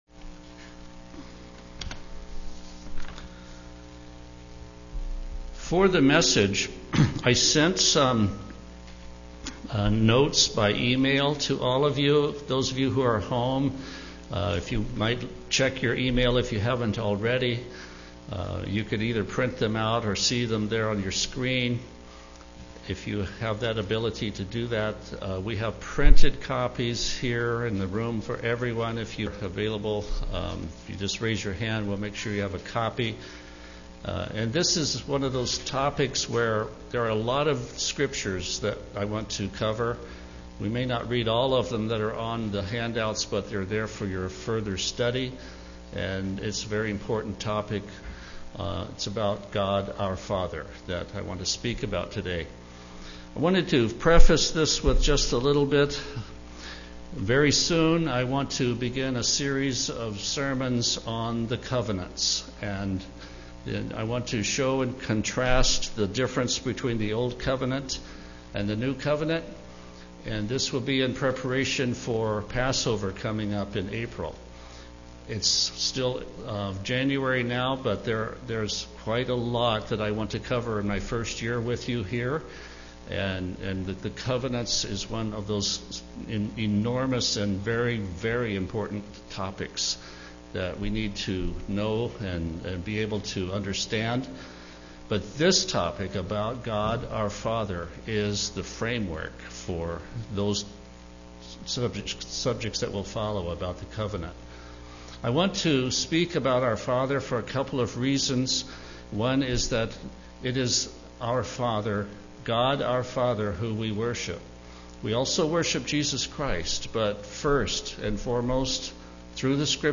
UCG Sermon Studying the bible?
Given in Olympia, WA